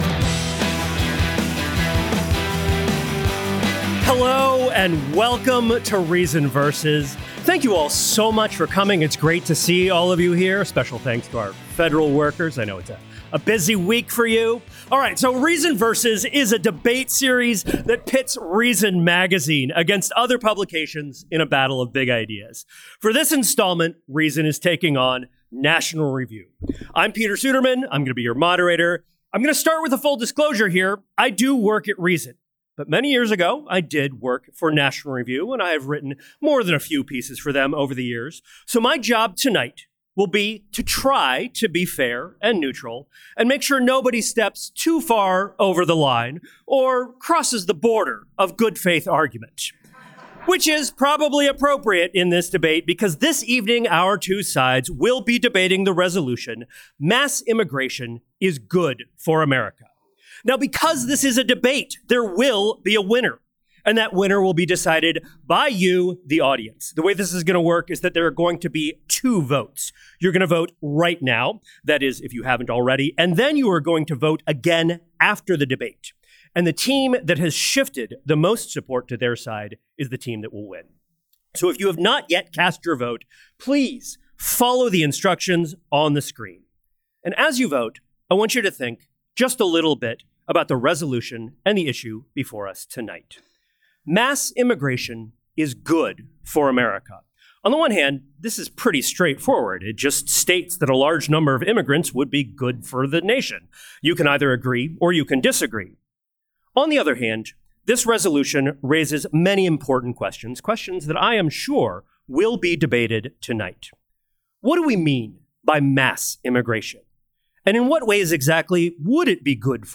Resolution: Mass Immigration Is Good for America